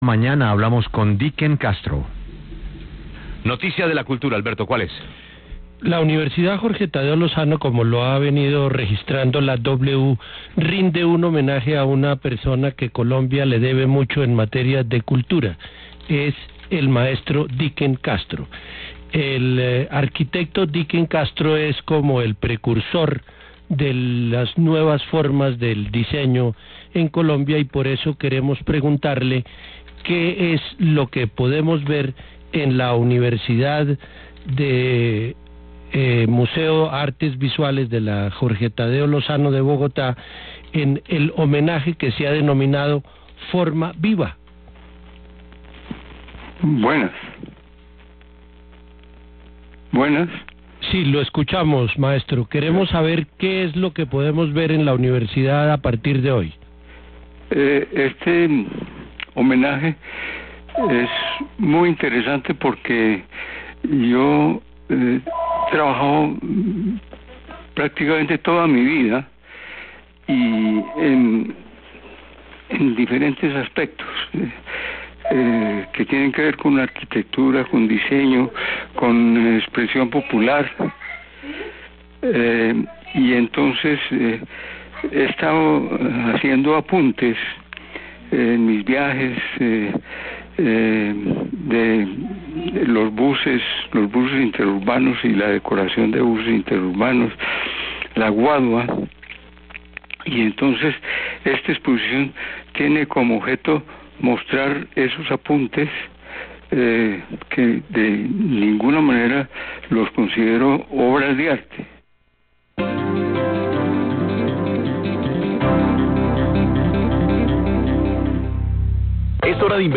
W Radio recordó una entrevista a Dicken Castro sobre su exposición "Forma Viva" reconocimiento que se le rindió en la Tadeo años atrás y de la que señaló que "Este homenaje es muy interesante, porque yo he trabajado prácticamente toda mi vida y en diferentes aspectos que tienen que ver con la arquitectura, el diseño, con expresión popular; y entonces he estado haciendo apuntes en mis viajes, en los buses interurbanos y la decoración de buses.